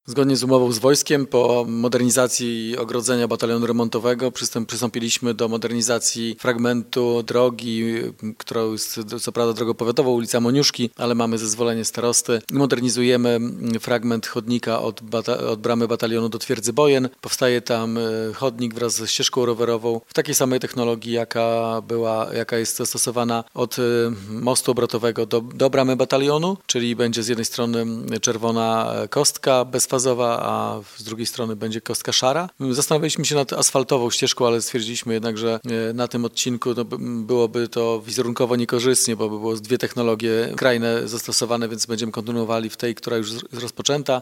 – Po zakończeniu prac zadowoleni będą nie tylko piesi, ale i cykliści, powstanie tam również ścieżka rowerowa – mówi burmistrz Giżycka Wojciech Iwaszkiewicz.